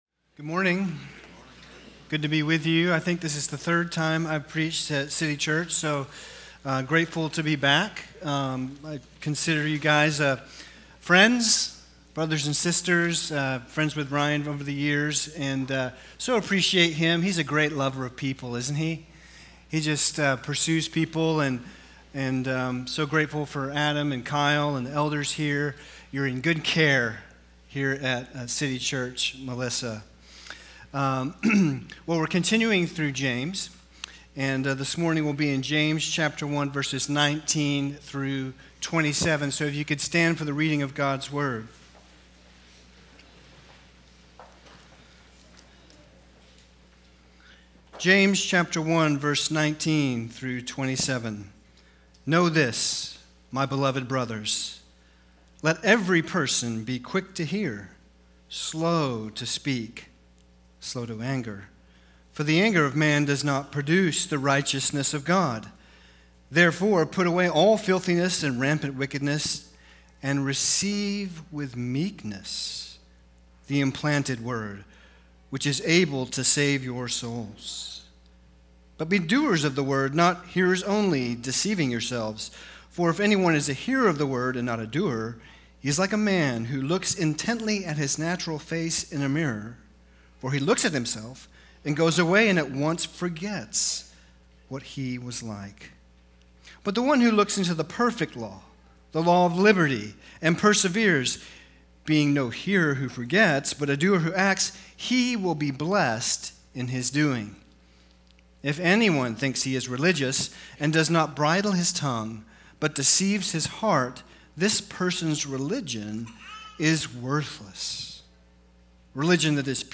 CCM+Sunday+Sermon+6.22.25.mp3